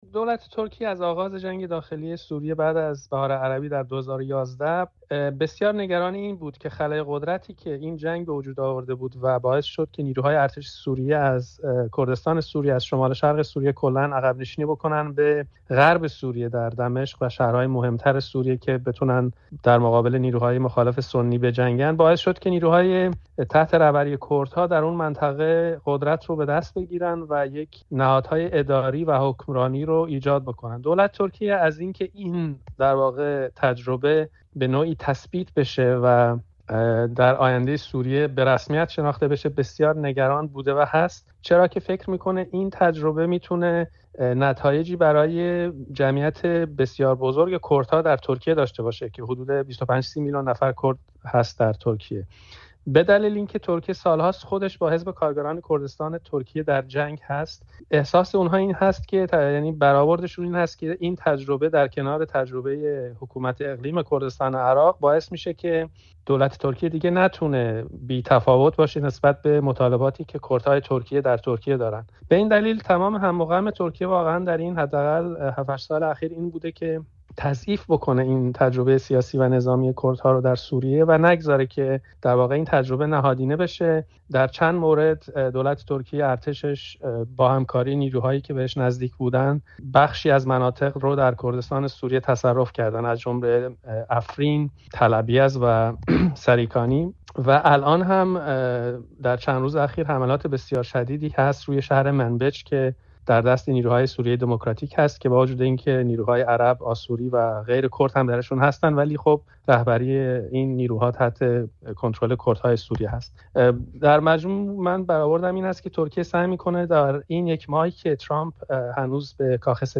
تحلیلگر مسائل بین الملل و ساکن بریتانیا گفت‌وگو کرده‌ایم.